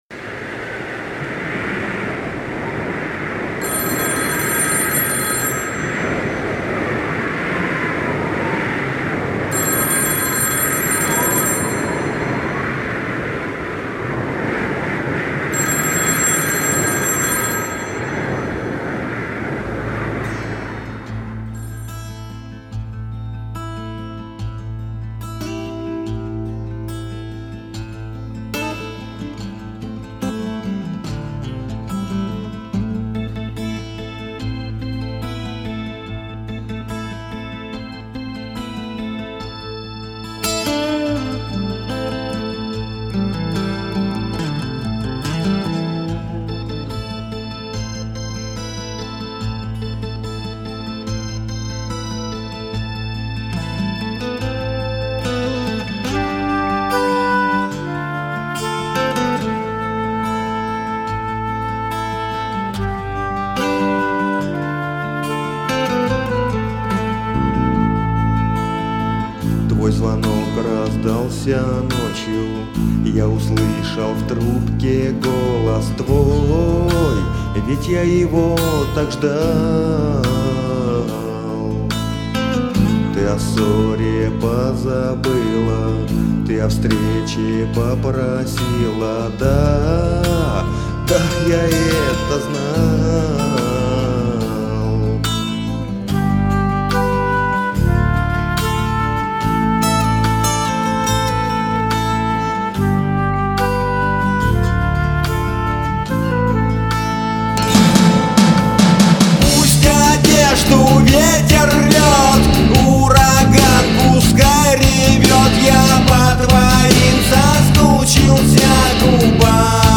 Жанр: Панк-рок